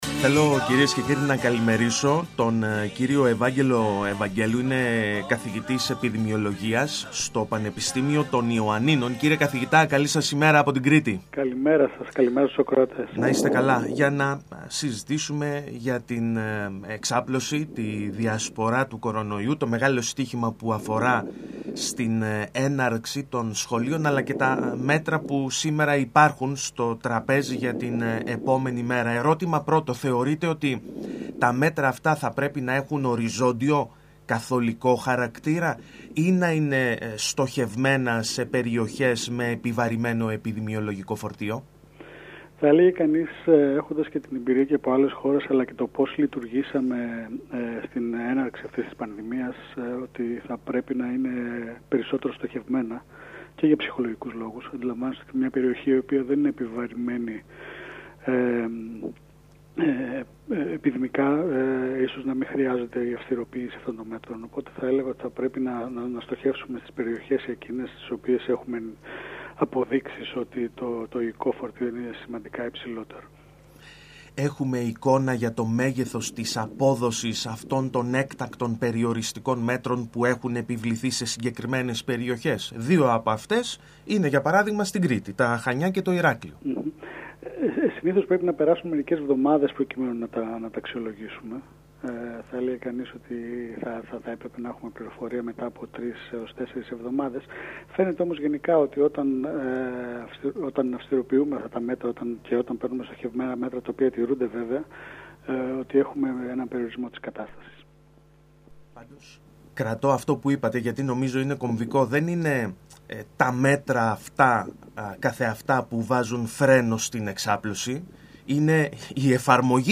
Για την πορεία της πανδημίας του κορωνοϊού στη χώρα, τα μέτρα αποφυγής εξάπλωσής του αλλά την σημασία τήρησής τους τον δύσκολο χειμώνα που έρχεται μίλησε στον ΣΚΑΪ Κρήτης